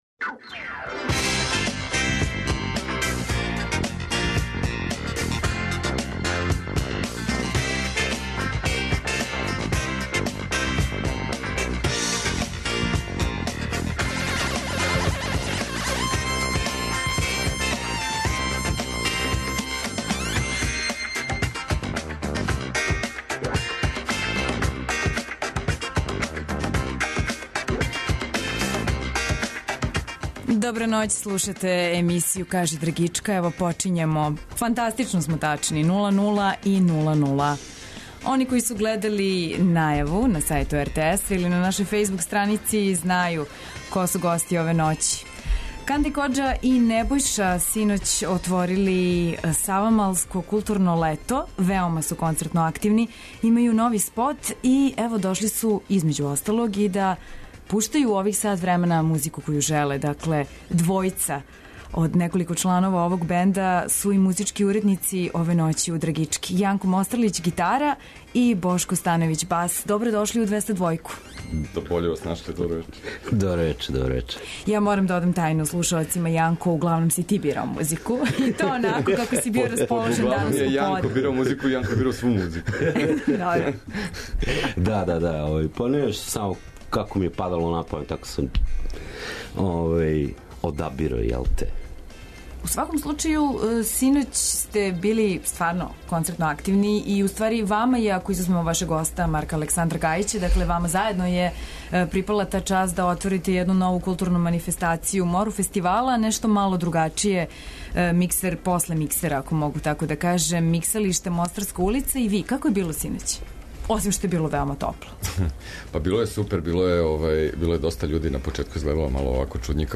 Вечерас нам у госте долази бенд Канда, Коџа и Небојша.
Одмах пошто су концертно отворили "Савамалско културно лето" стижу у студио Двестадвојке.